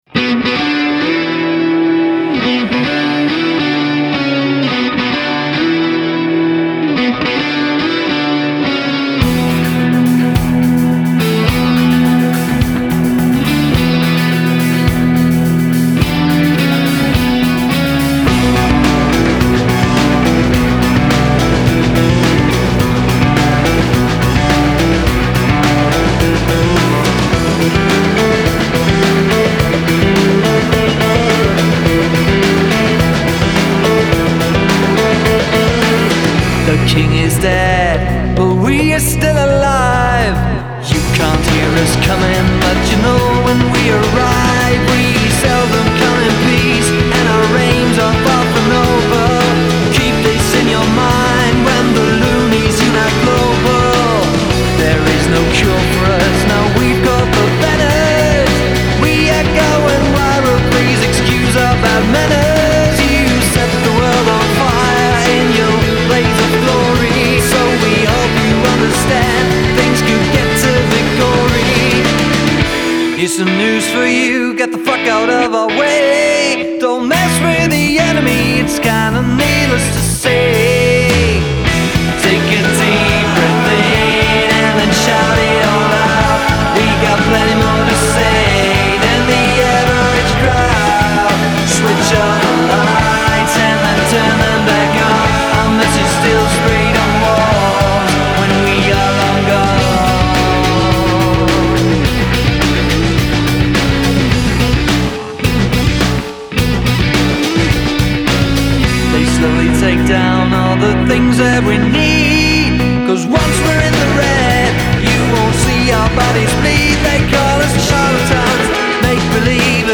Ist das Indie, ist das Pop?